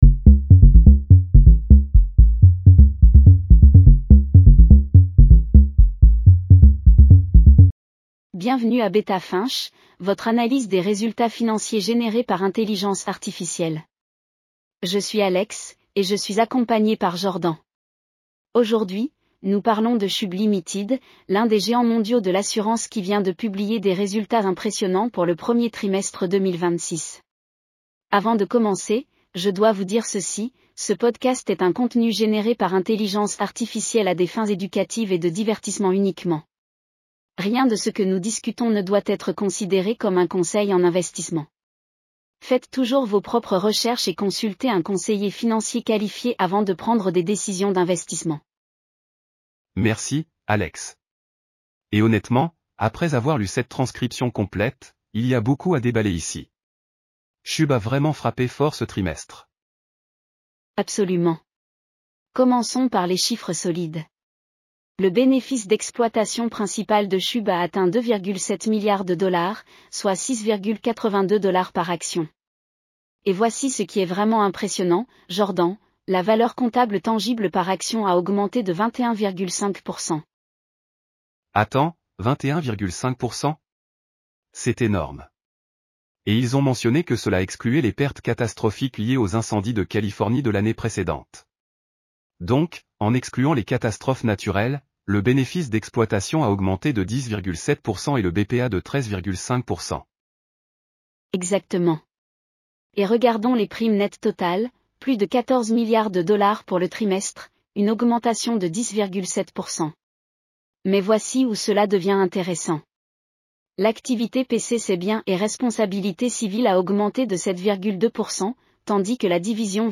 Bienvenue à Beta Finch, votre analyse des résultats financiers générée par intelligence artificielle.